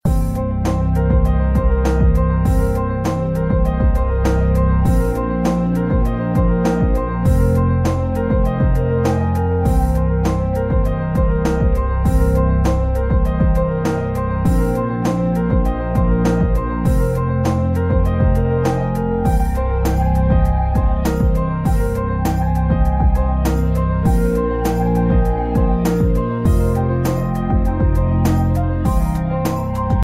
Free-Ambient-Loop-1.mp3